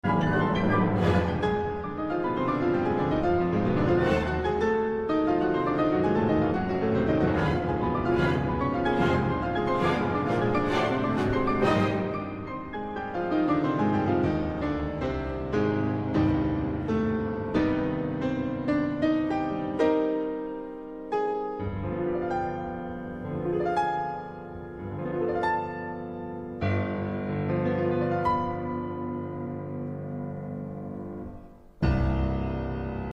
classical music
The energy is explosive, the passion is palpable